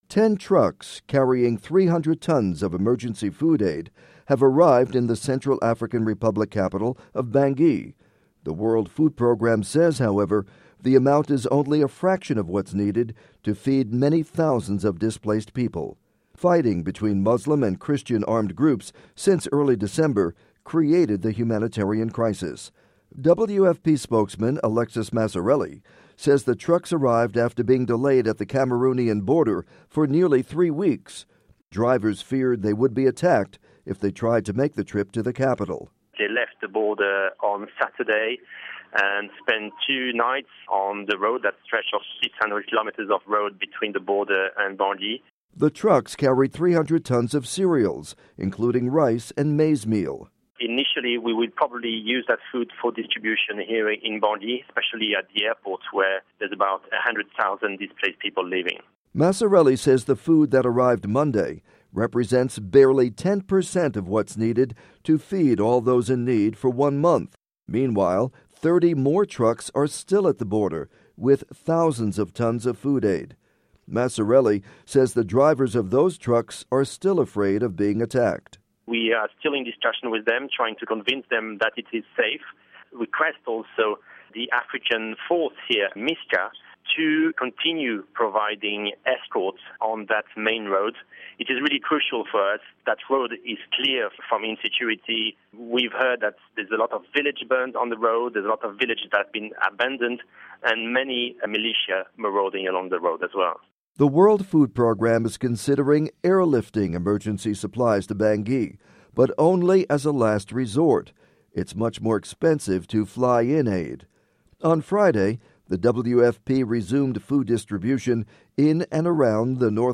report on food aid delivery in Bangui